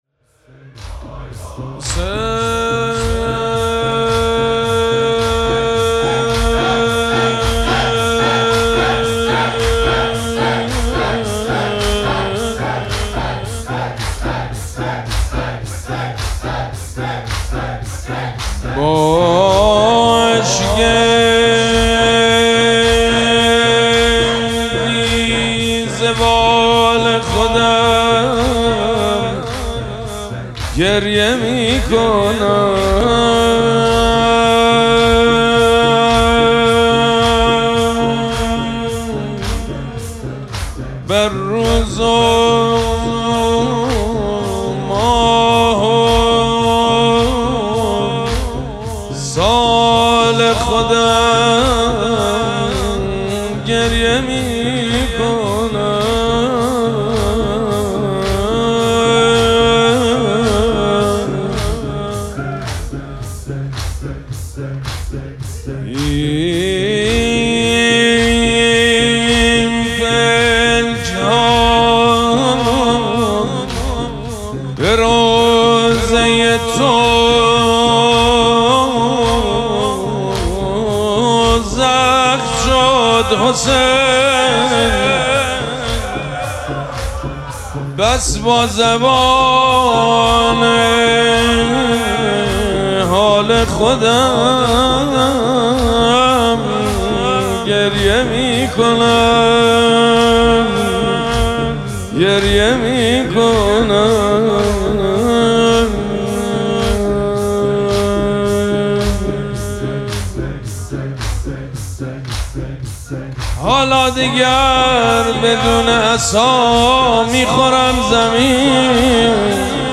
مراسم عزاداری شام شهادت حضرت زینب سلام‌الله‌علیها
نغمه خوانی
حاج سید مجید بنی فاطمه